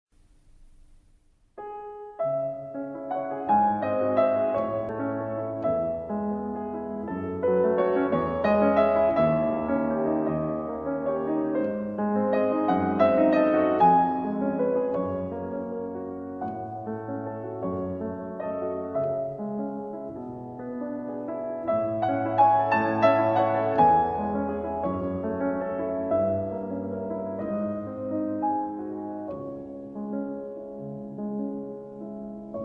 Allegretto
Fanny-Mendelssohn-Hensel-Melody-in-C-sharp-minor-Op.4-No.2-AudioTrimmer.com_.mp3